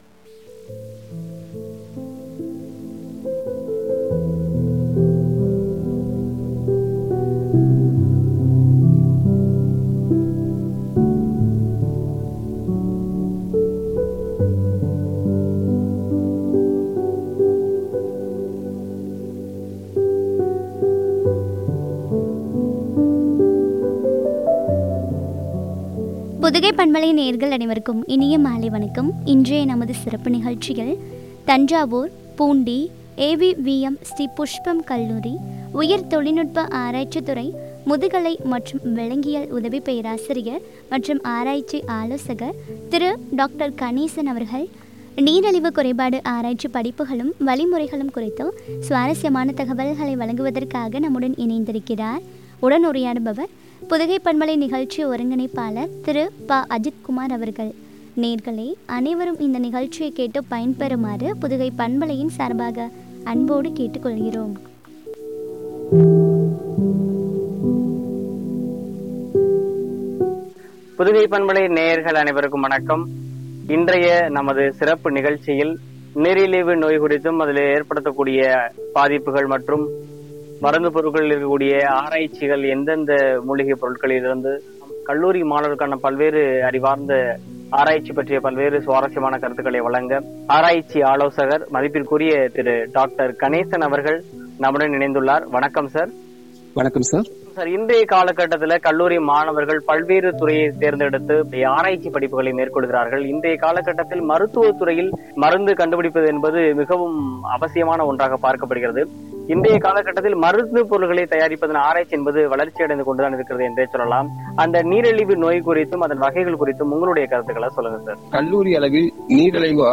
உரையாடல்